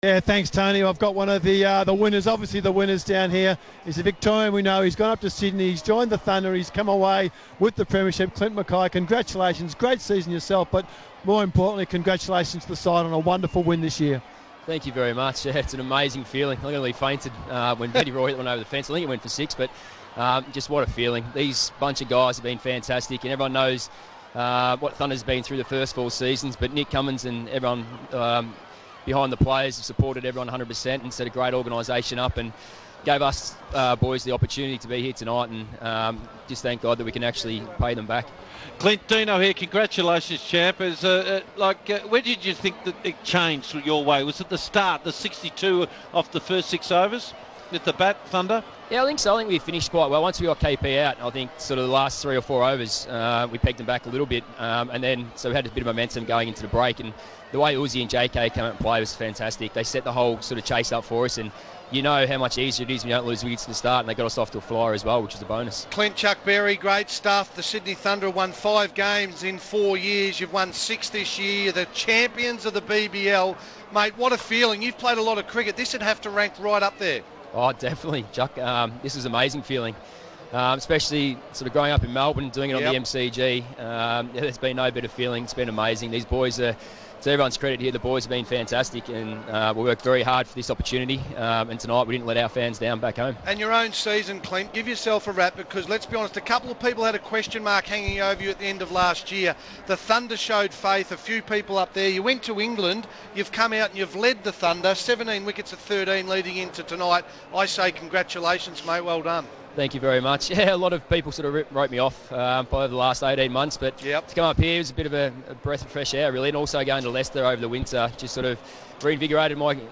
INTERVIEW: Sydney Thunder quick Clint McKay talks after his side's victory in the BBL05 Final.